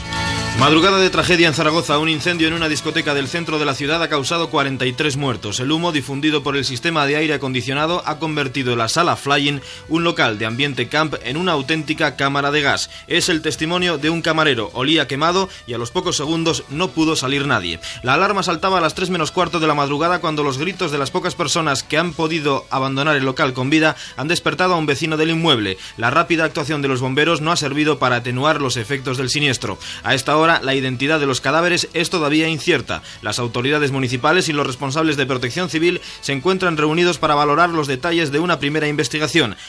Entrevista telefònica a l'alcalde en funcions de Saragossa Luis García Nieto.
Informatiu